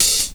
pcp_openhihat03.wav